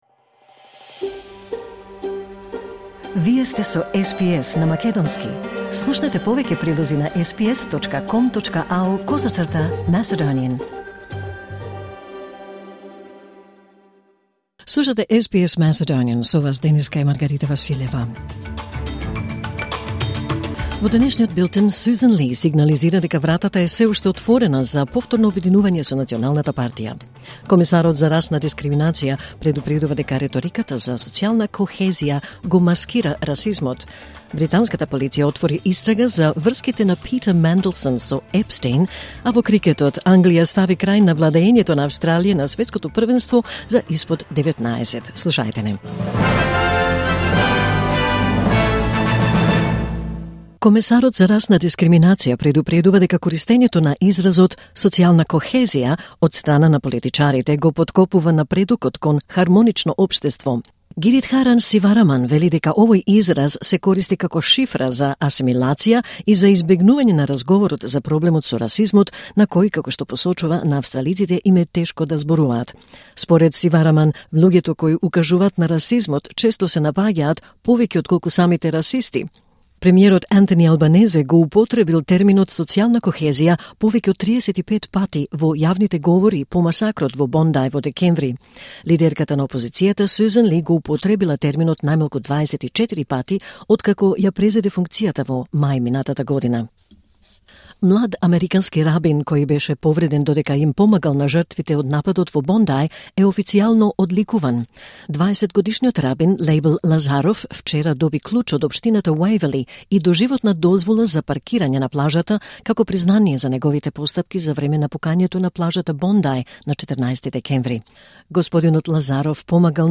Вести на СБС на македонски 4 февруари 2026